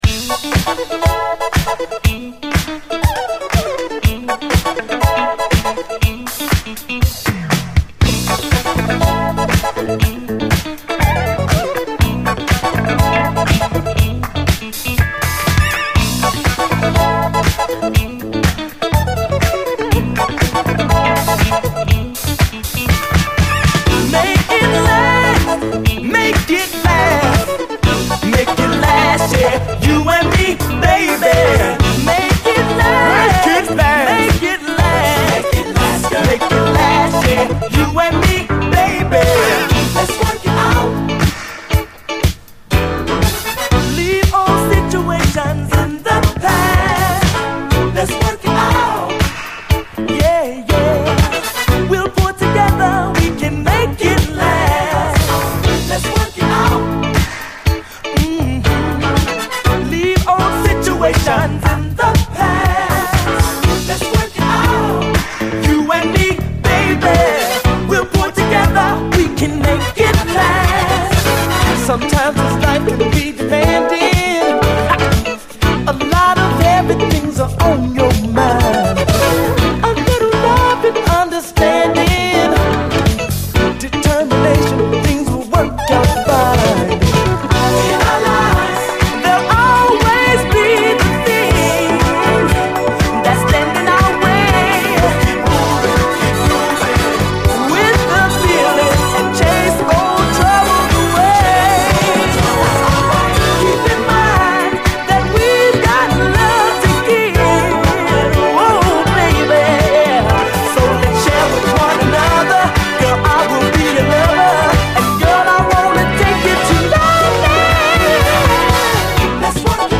SOUL, 70's～ SOUL, DISCO
ピュンピュンと強烈にシンセが主張してくるアーバン・ブギー！
インストもカッコいいです！